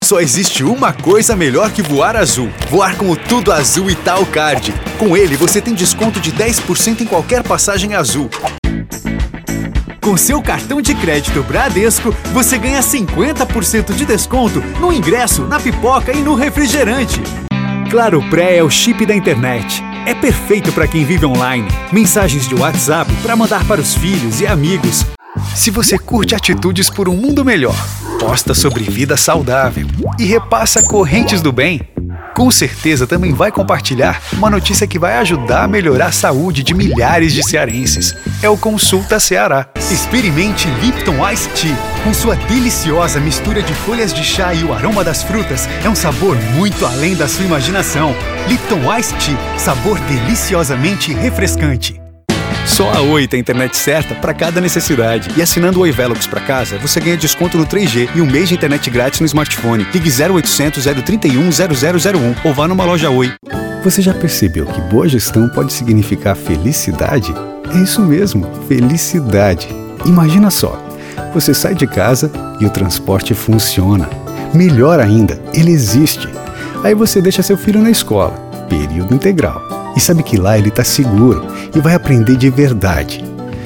Portuguese (Brazilian)
Warm, friendly, versatile and professional voice tone.
Accurate
Articulate
Authoritative